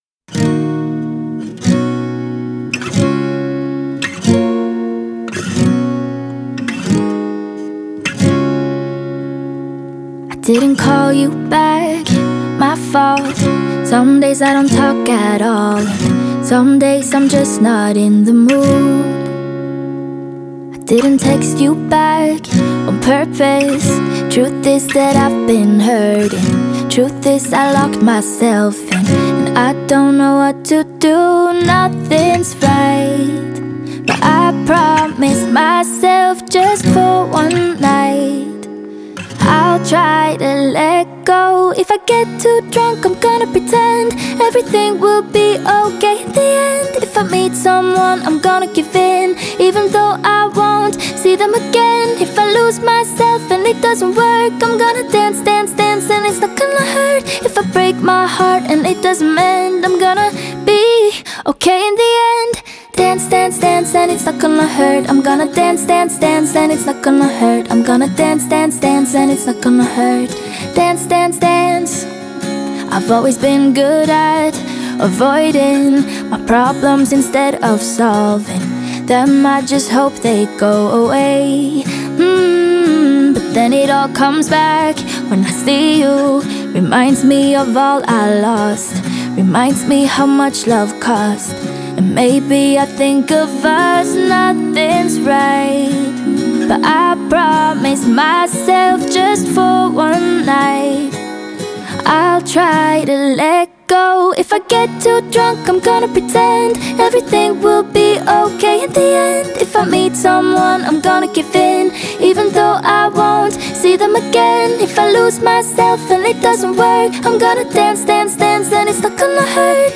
норвежской певицы